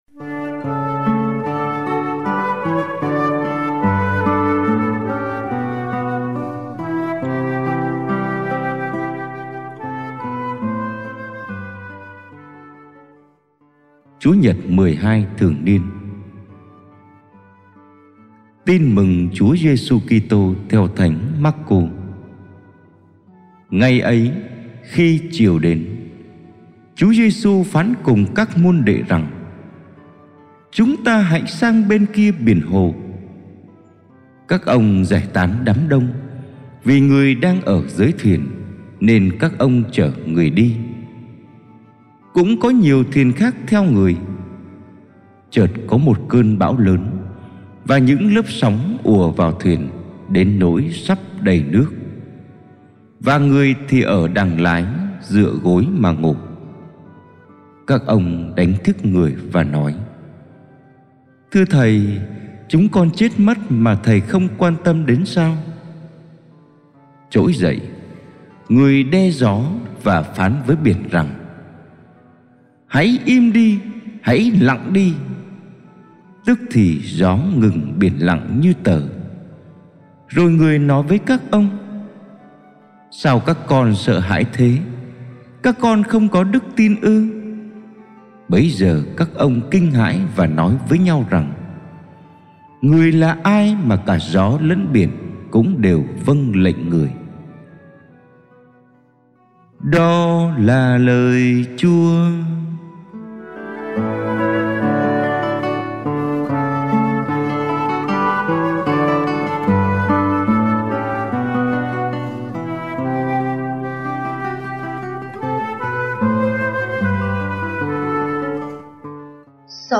Bài giảng lễ Chúa nhật 13 mùa thường niên B - 2021